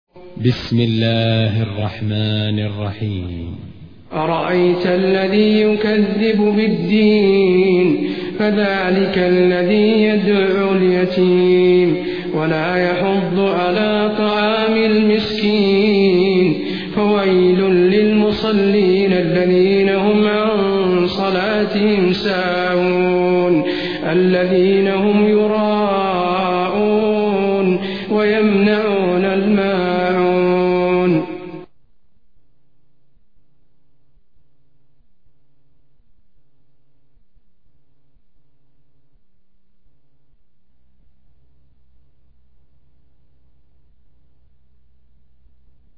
1426 صلاة التراويح عام 1426هـ من المسجد النبوى